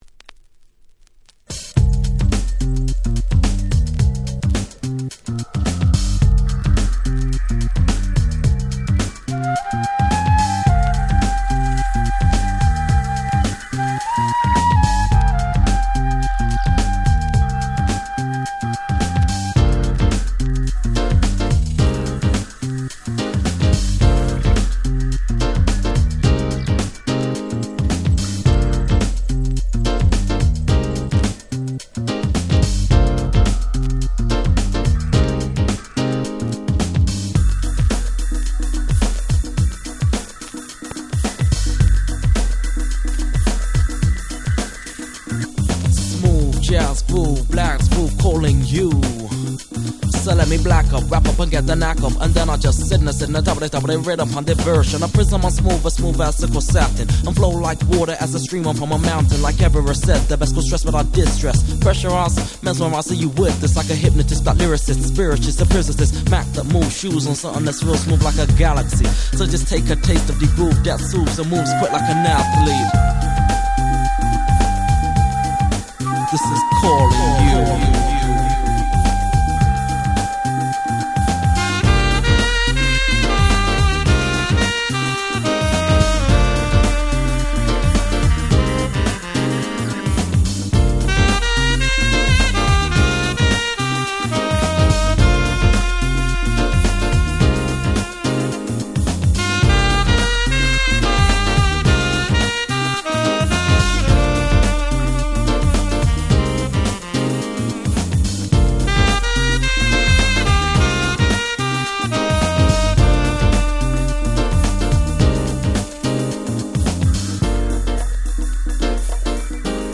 91' Very Nice Acid Jazz !!
ピアノとSaxの音色が超絶Smoothで超絶Jazzy !!
アシッドジャズ 90's R&B